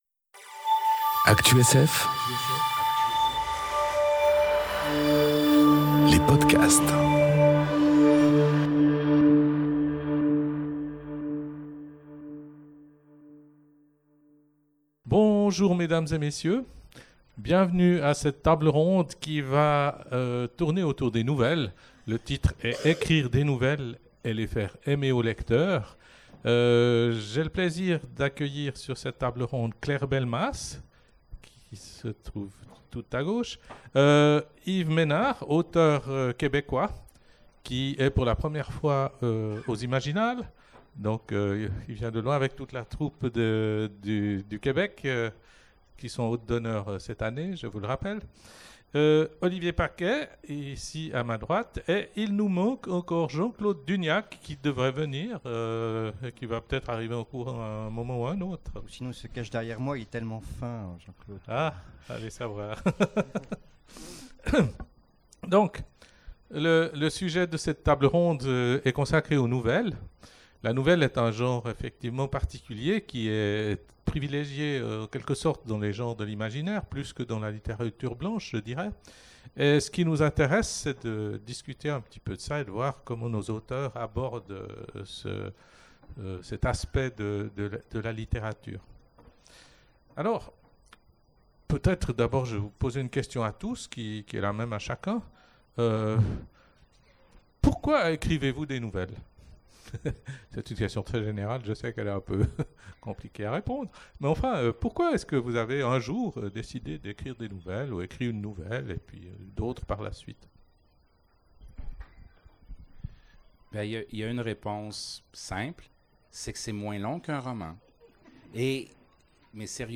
Conférence Écrire des nouvelles... Et les faire aimer aux lecteurs ! enregistrée aux Imaginales 2018